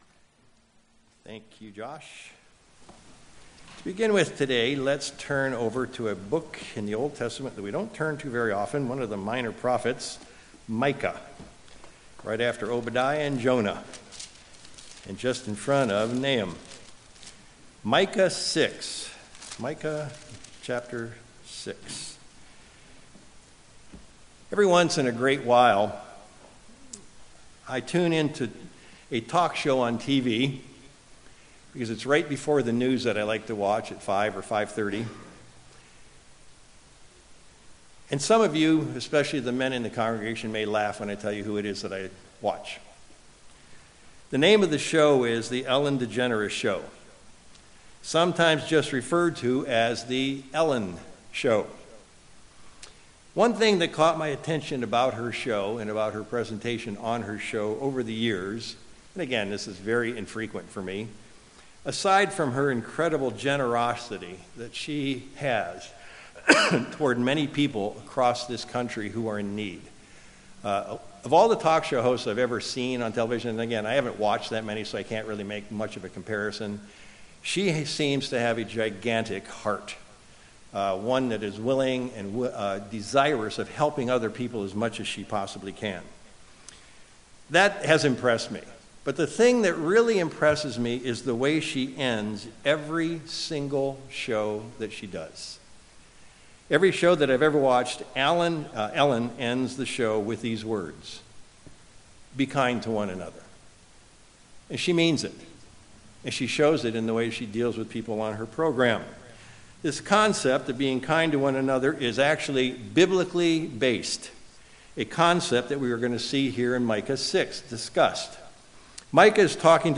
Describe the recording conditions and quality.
Given in Sacramento, CA